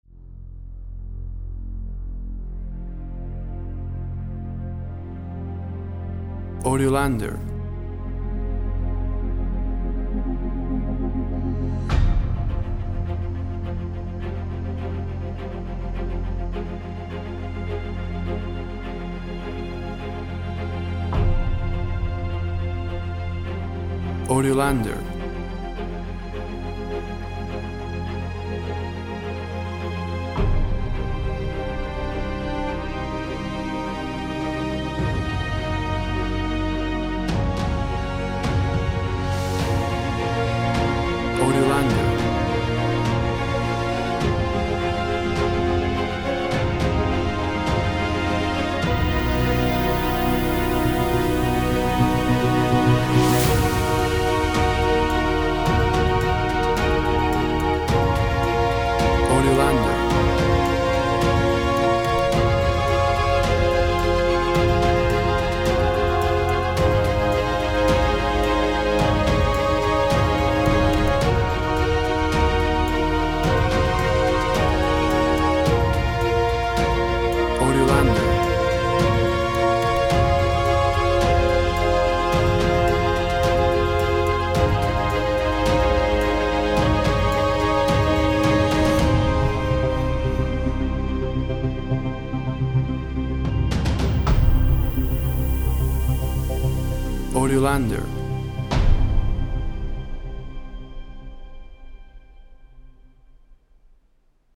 Modern cinematic epic track, orchestra and Fx sounds.
Tempo (BPM) 106